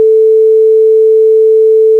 소리의 높이는 라(A)음입니다.
우리가 물리시간에 배우는 sin 함수는 소리가 아주 단조롭습니다. TV에서 방송종료 후 나오는 소리에서나 들을 수 있을 것 같은 소리네요.
sine_A.mp3